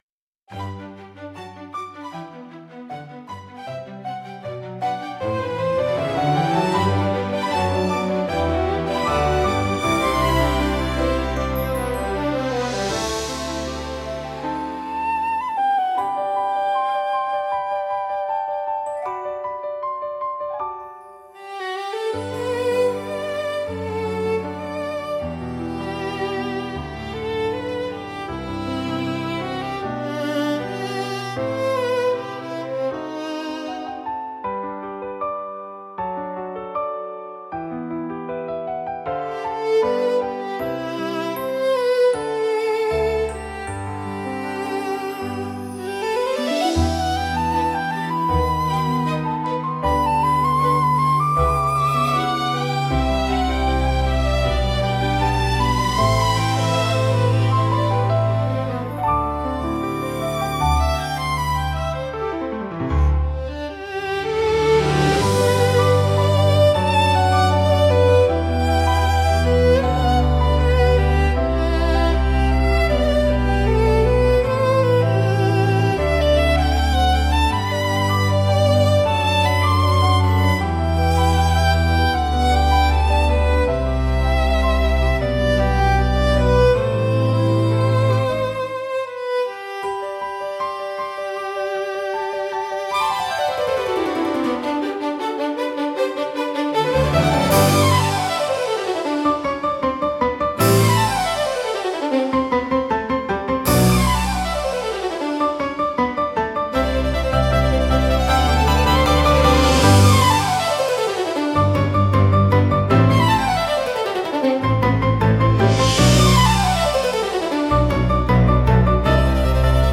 ロマンティックで華やかな場にぴったりのジャンルです。